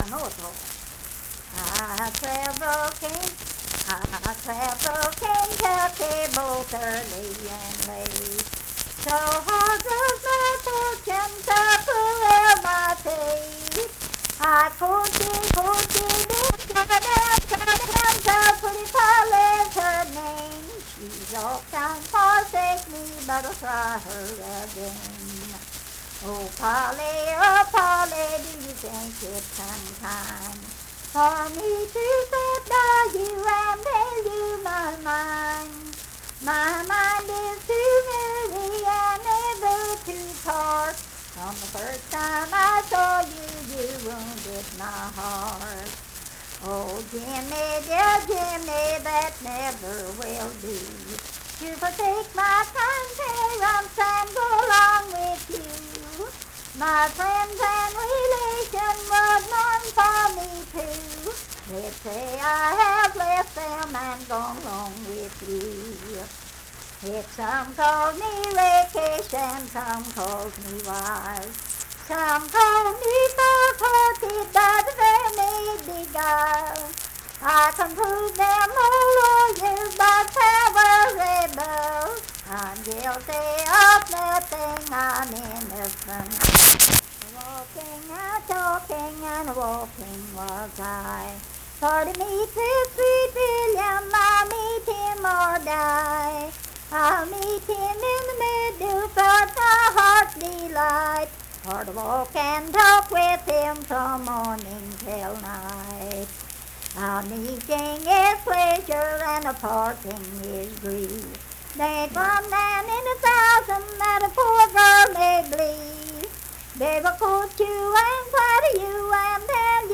Unaccompanied vocal music performance
Verse-refrain 9(4).
Voice (sung)